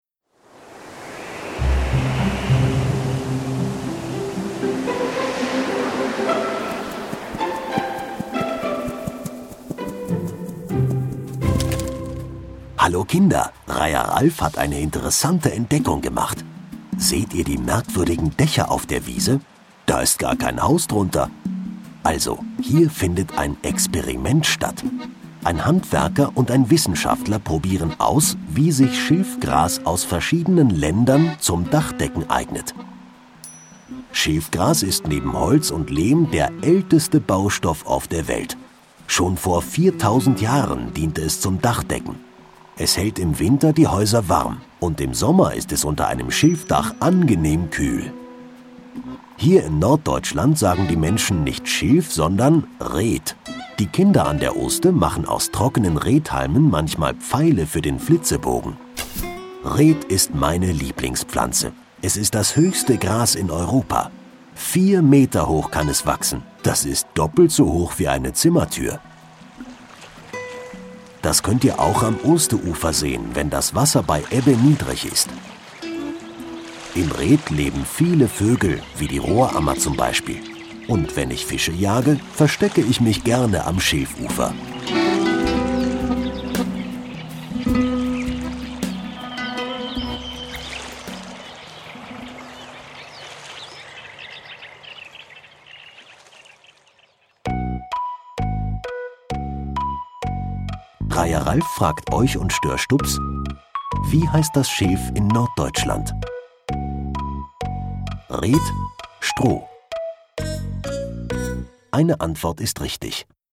Experiment Schilf - Kinder-Audio-Guide Oste-Natur-Navi